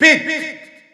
File File history File usage Pit_French_Announcer_SSBU.wav  (WAV audio file, length 0.9 s, 768 kbps overall) Summary [ edit ] Announcer pronouncing Pit .
Category:Pit (SSBU) Category:Announcer calls (SSBU) You cannot overwrite this file.
Pit_French_Announcer_SSBU.wav